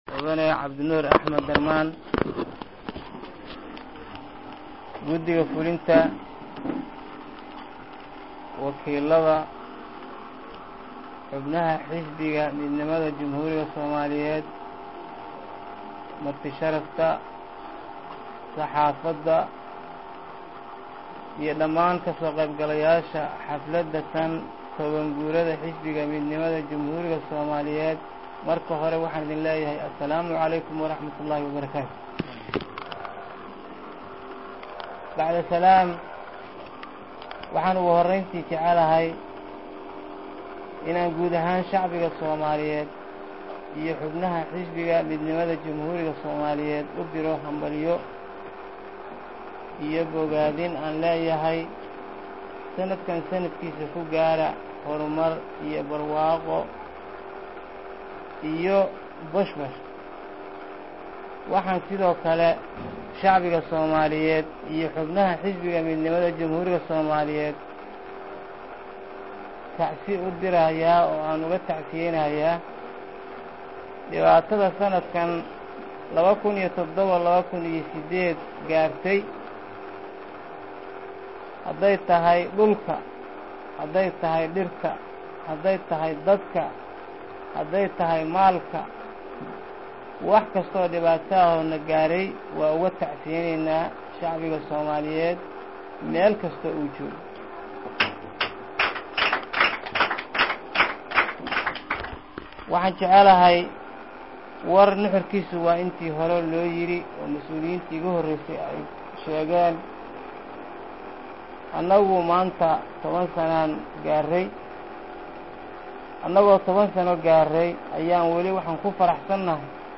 Xisbiga midnimada jamhuuriga soomaaliyeed oo ah xisbigii ugu horreeyey oo si dimoqraadi ah dalka looga aasaasay, ayaa manta waxaa laga xusay 10 guuradii ka soo wareegtay markii dhidibada loo taagay jiritaanka xisbigaas. Munaasabad manta lagu maamusayey xuska maalinta xisbiga ayaa waxaa ay ka dhacday xarunta xisbiga ee xaafadda xamar weyne